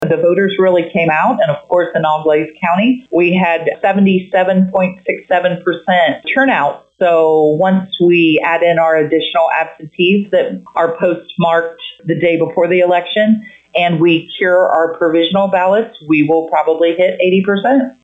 To hear comments with Auglaize County Board of Elections Director Michelle Wilcox: